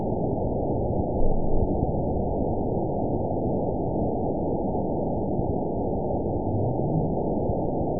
event 920719 date 04/06/24 time 01:02:57 GMT (1 year, 1 month ago) score 8.97 location TSS-AB03 detected by nrw target species NRW annotations +NRW Spectrogram: Frequency (kHz) vs. Time (s) audio not available .wav